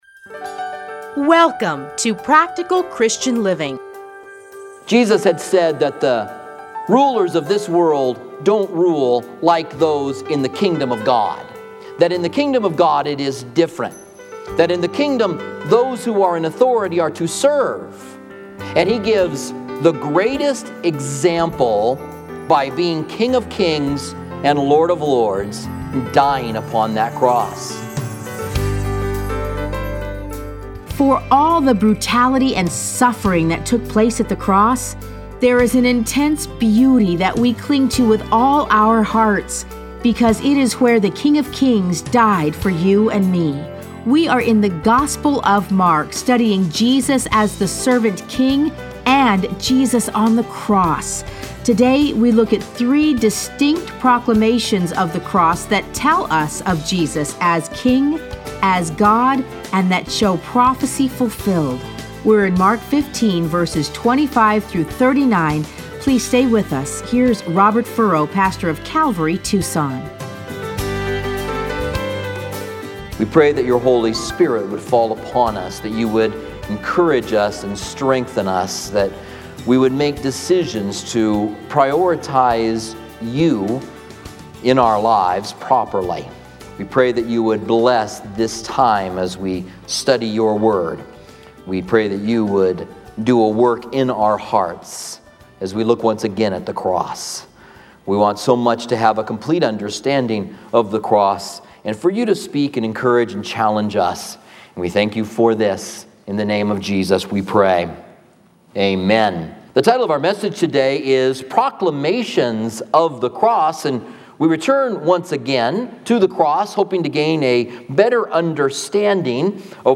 Listen to a teaching from Mark 15:25-39.